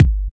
• Original Bass Drum Sound G Key 31.wav
Royality free kick sample tuned to the G note. Loudest frequency: 239Hz
original-bass-drum-sound-g-key-31-s17.wav